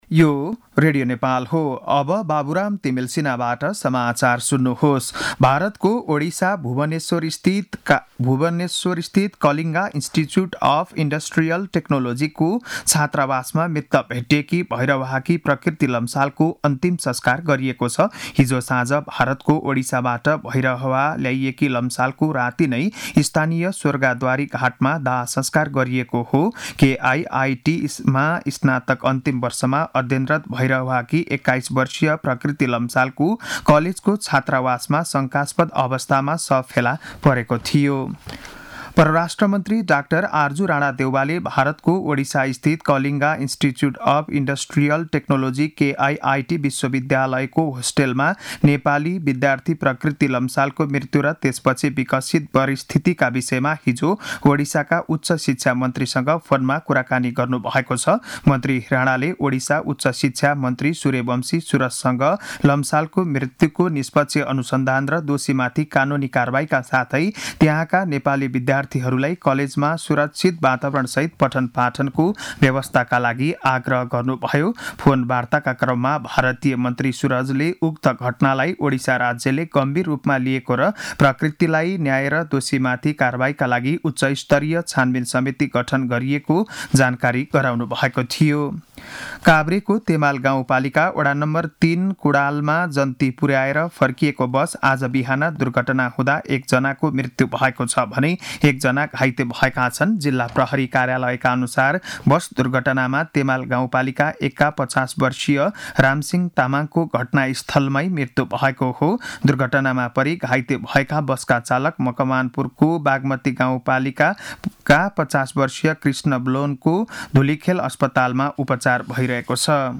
बिहान ११ बजेको नेपाली समाचार : ९ फागुन , २०८१